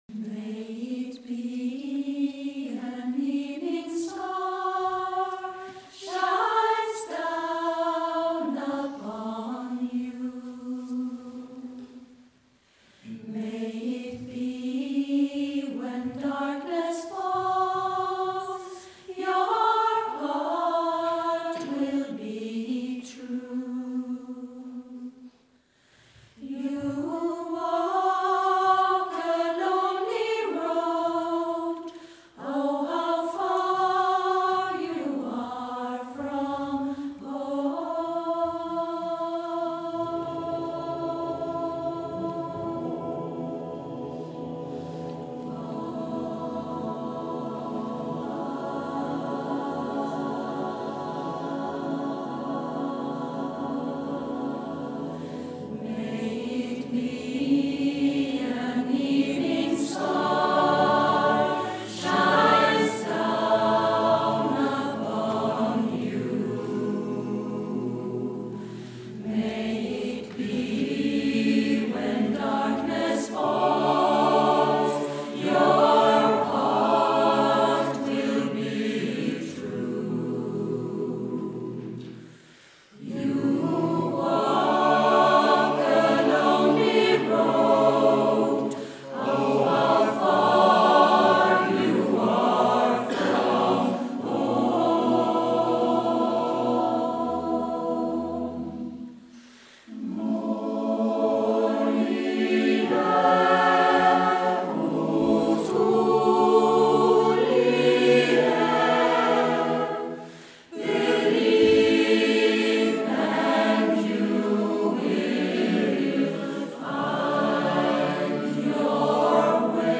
Trettondedagen 2017 Tåssjö kyrka